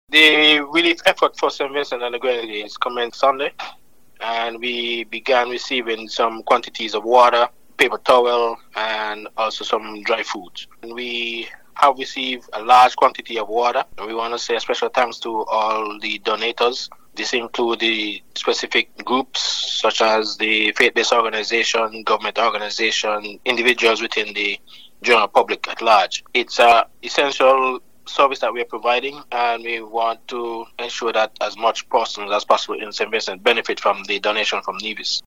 The VONNEWSLINE spoke to Director of the NDMD – Mr. Brian Dyer on how the relief supplies drive went: